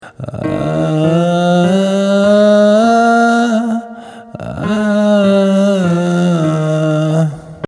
Edge ton ma prenikavý kovový zvuk.
Edge cvičenie – Brumendo  na päťtónovej stupnici.
Muži:
Najprv počujete ako sa hlas prerušuje.
EDGE-MUZI-PRIKLAD.mp3